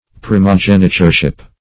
Search Result for " primogenitureship" : The Collaborative International Dictionary of English v.0.48: Primogenitureship \Pri`mo*gen"i*ture*ship\, n. The state or privileges of the firstborn.
primogenitureship.mp3